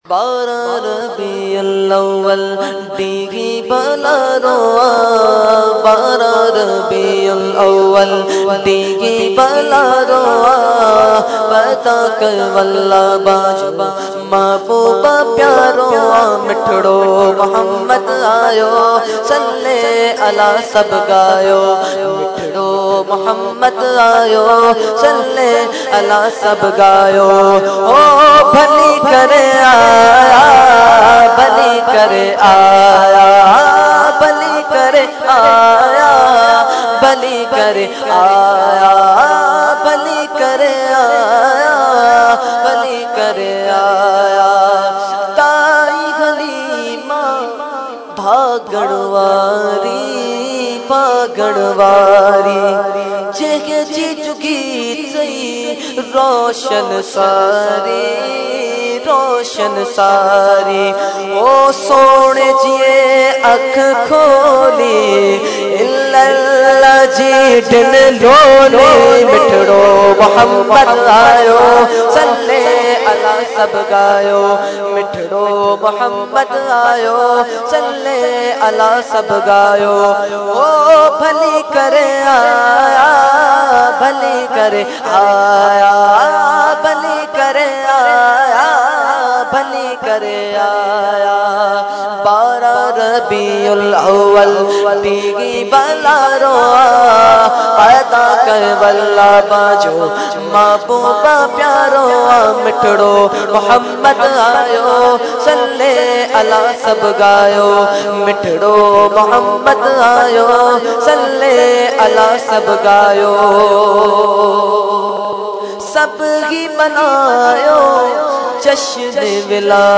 Category : Naat | Language : SindhiEvent : Mehfil Muhammad Masjid Firdous Colony 15 March 2014